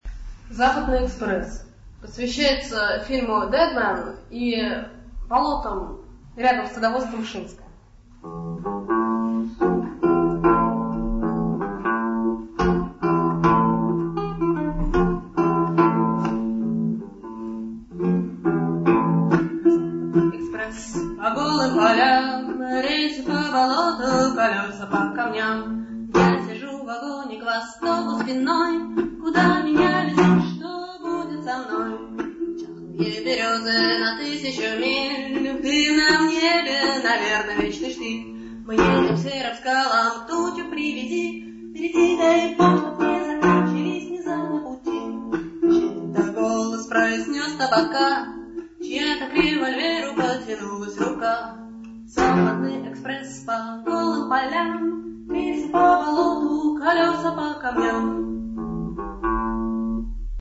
Запись с концерта